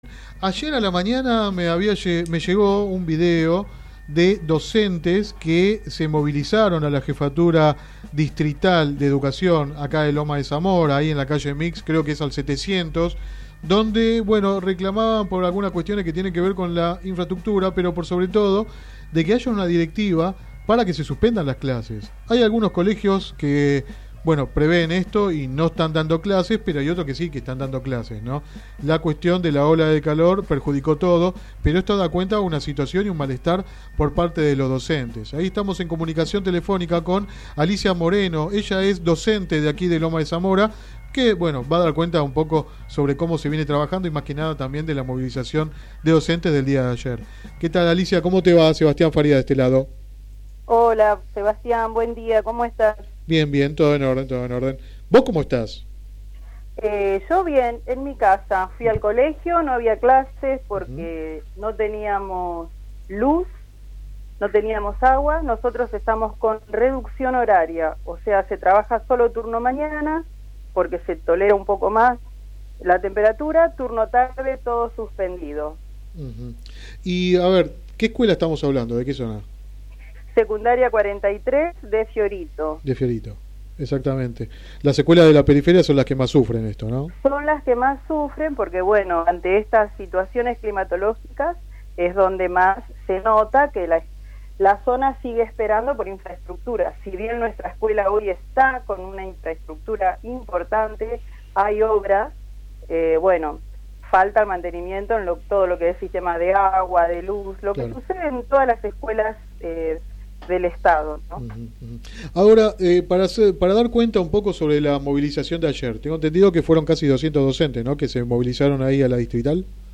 entrevista radial